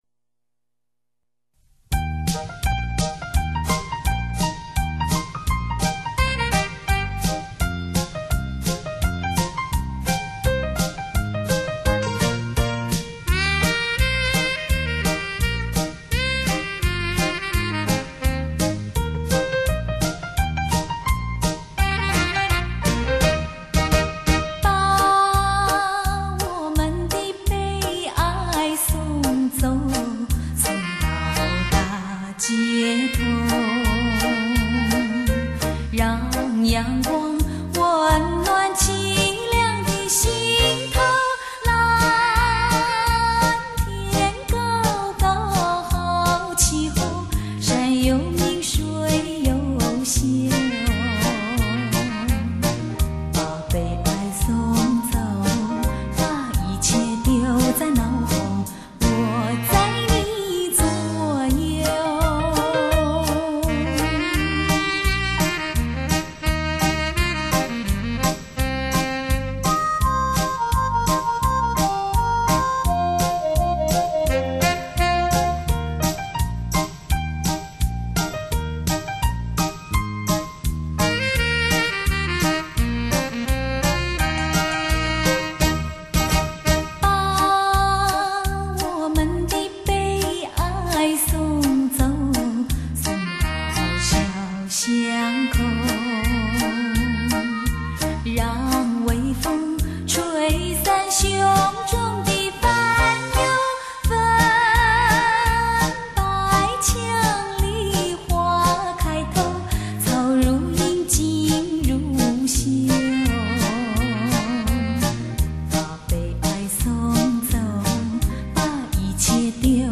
令人心醉的情歌 最最坦诚真实的声音
精彩绽放无限的音乐 优美旋律
引发无限幽思 清纯歌声使人顿然忘忧....